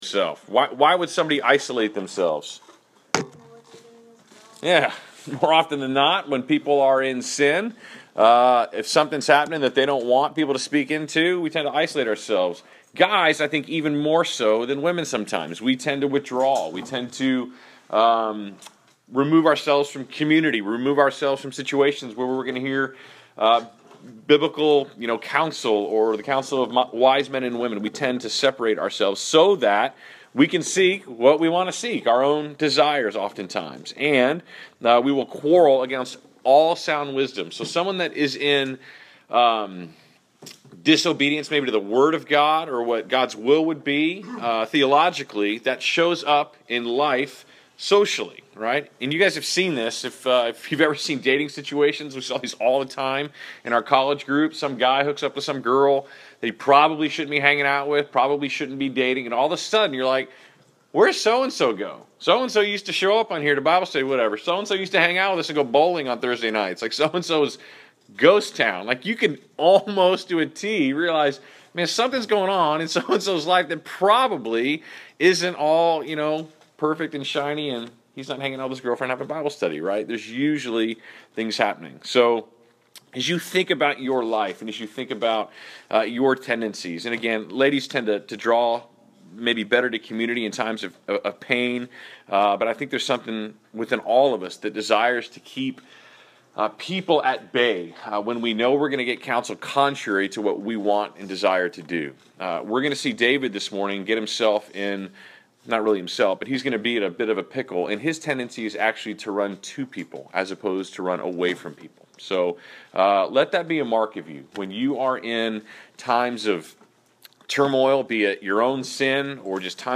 Class Session Audio March 16